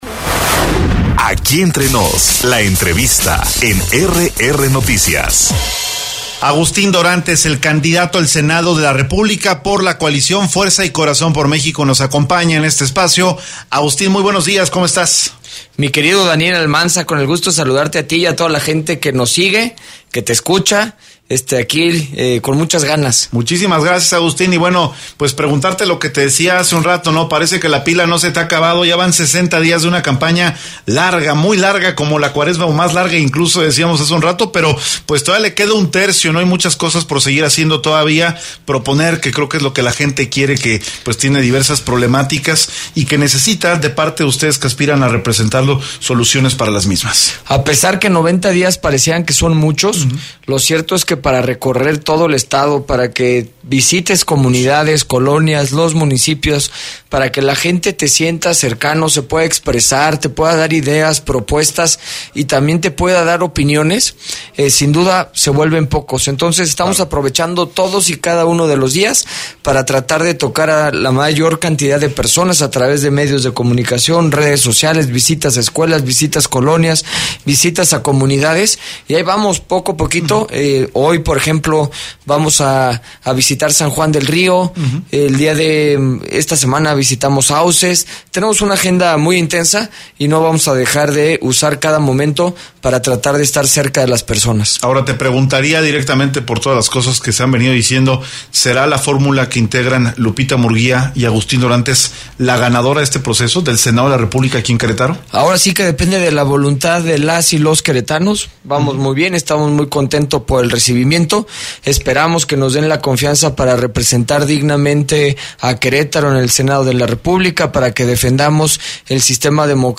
EntrevistasPodcast